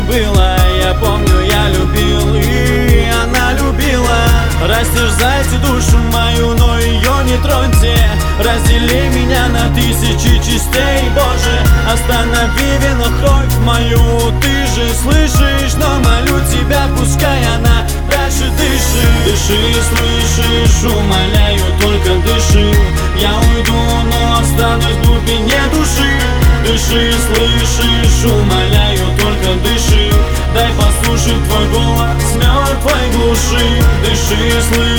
Жанр: Поп музыка / Русский поп / Русские